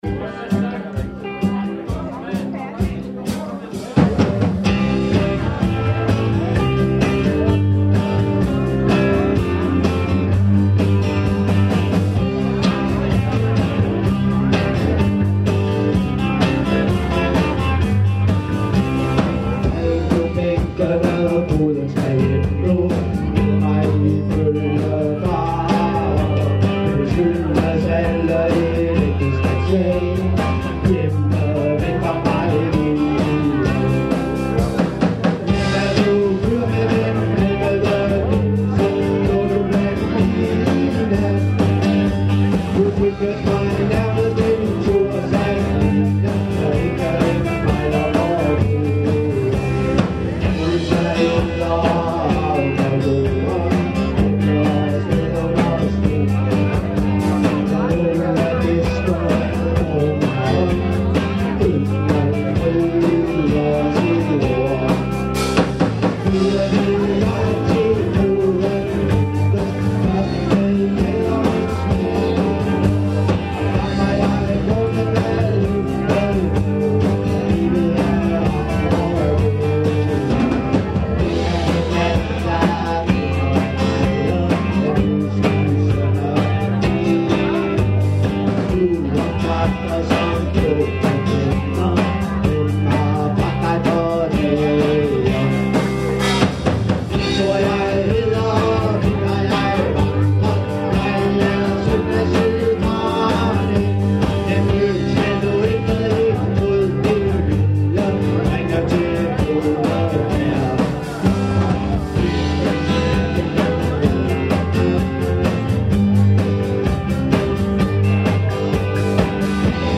Live Festival